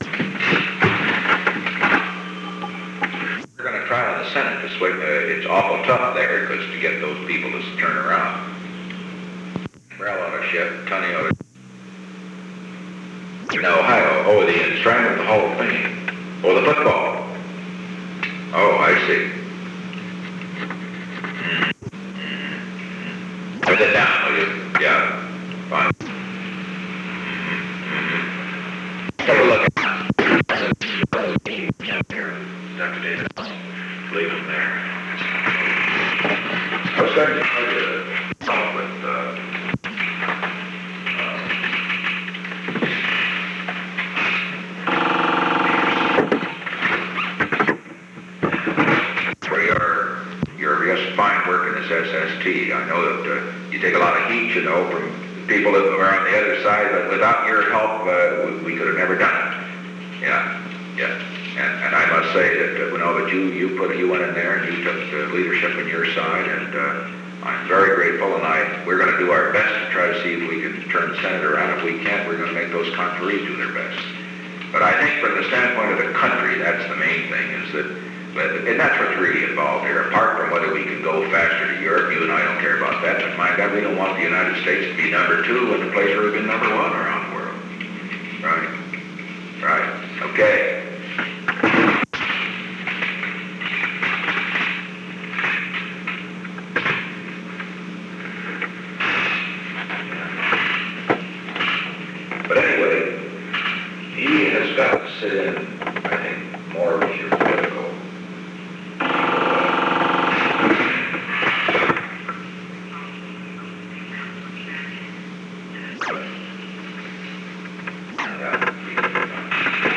Recording Device: Oval Office
The Oval Office taping system captured this recording, which is known as Conversation 498-001 of the White House Tapes.
The recording began while the meeting was in progress. Discontinuities appear in the original recording.